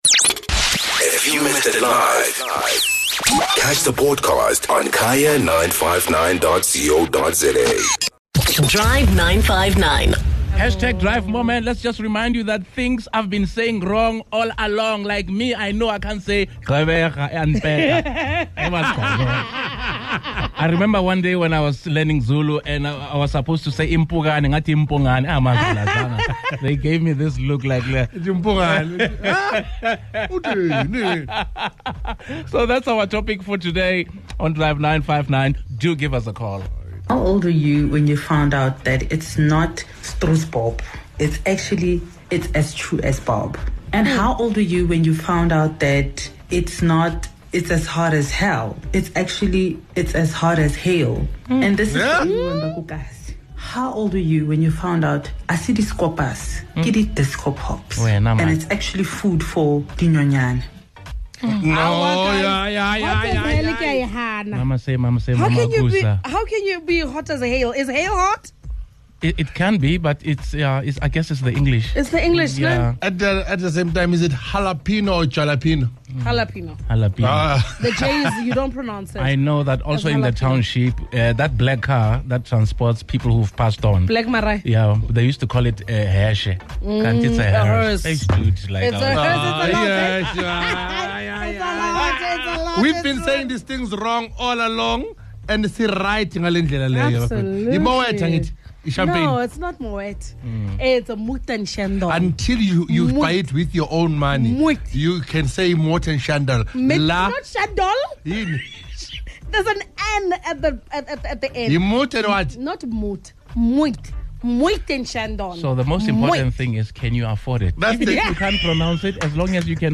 What are some of those words, phrases, song lyrics and names that we've been saying wrong, until one day someone gives you the correct way to say it? The Team and the listeners had the most hilarious takes on the matter!